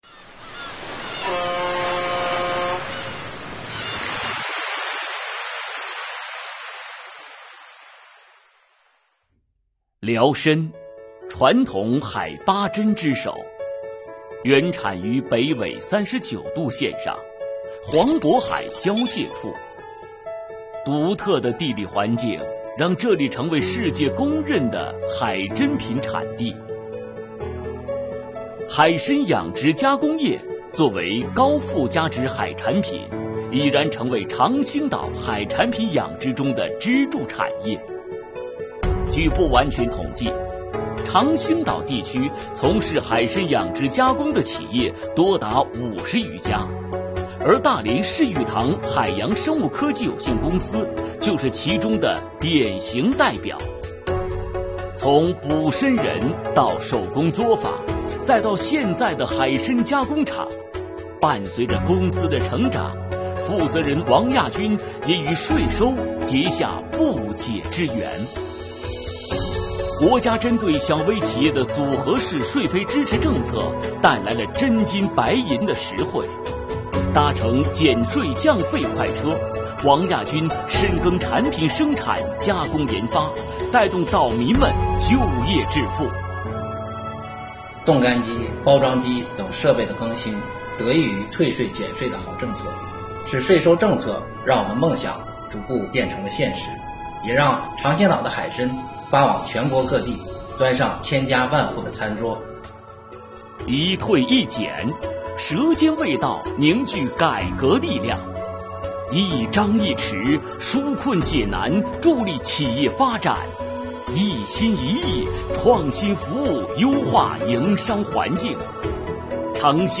画面衔接流畅，场景丰富，节奏和音乐把握恰当。